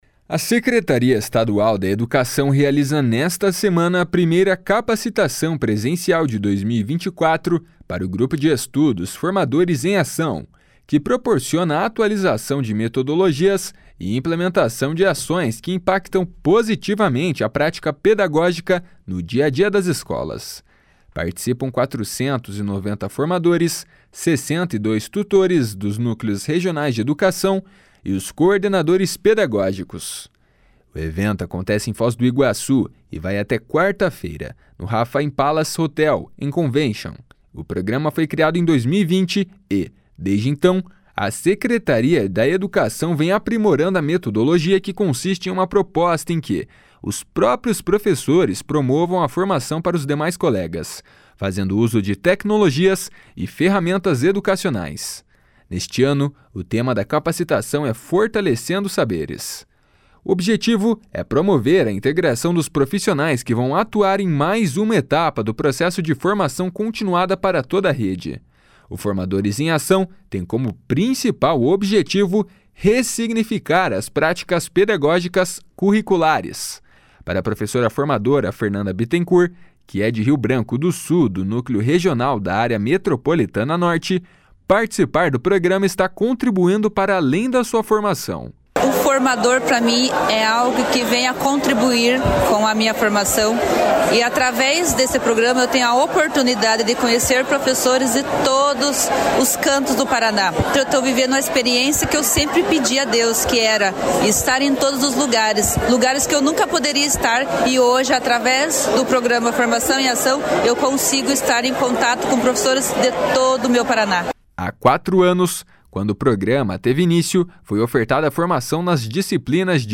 Há quatro anos, quando o programa teve início, foi ofertada a formação nas disciplinas de biologia, ciências, língua portuguesa e matemática, com mais de seis mil educadores cursistas e 400 professores responsáveis pela instrução. (Repórter: